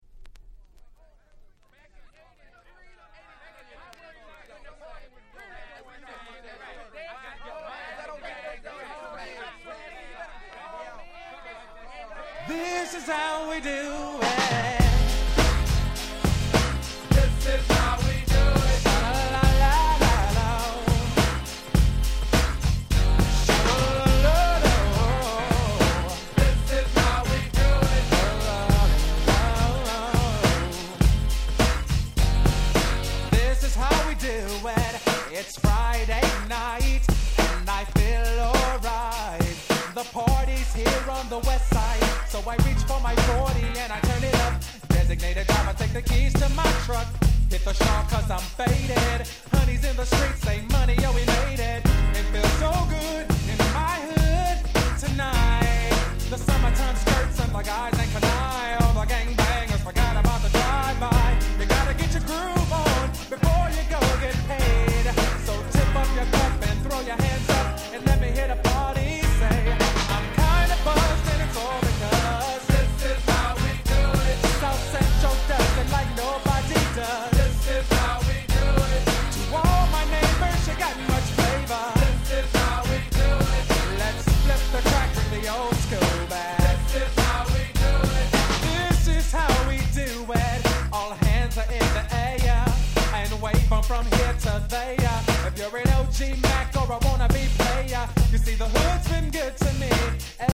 95' Super Hit R&B LP !!